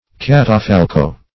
catafalco - definition of catafalco - synonyms, pronunciation, spelling from Free Dictionary Search Result for " catafalco" : The Collaborative International Dictionary of English v.0.48: Catafalco \Cat`a*fal"co\, n. [It.]
catafalco.mp3